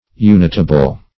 Unitable \U*nit"a*ble\, a. Capable of union by growth or otherwise.